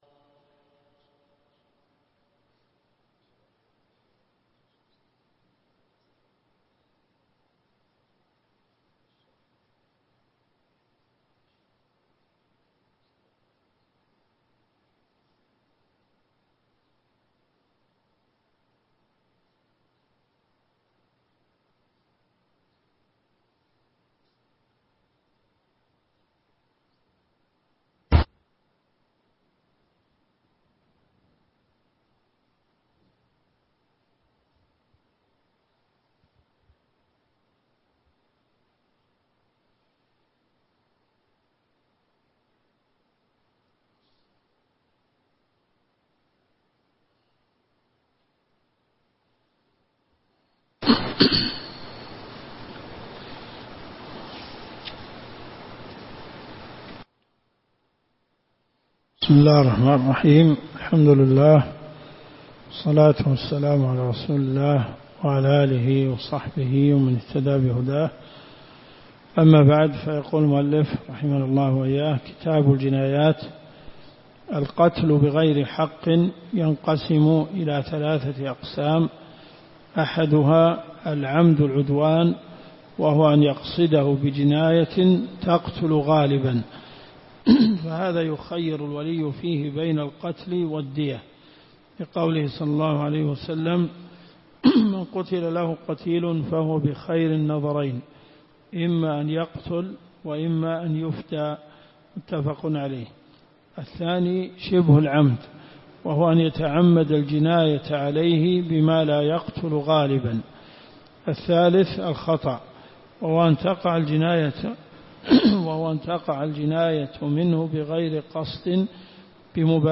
الرئيسية الكتب المسموعة [ قسم الفقه ] > (1) منهج السالكين .